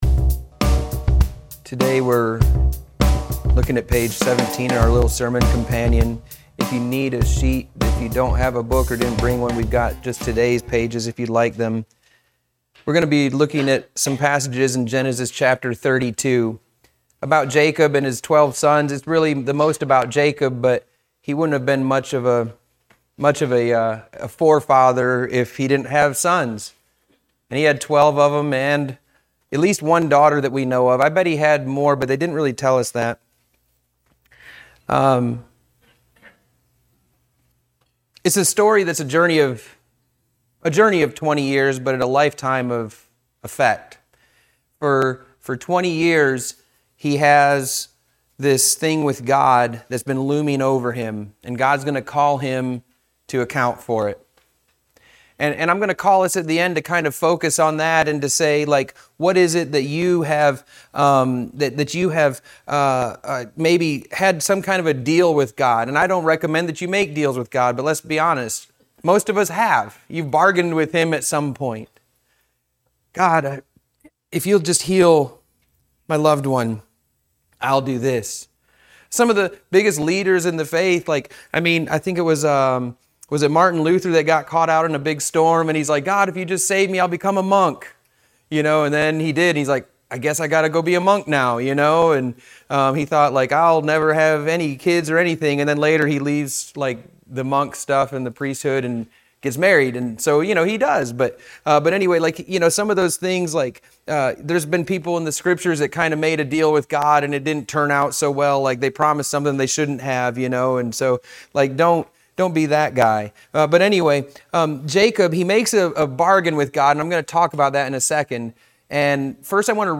Sunday School for Neh. 3-4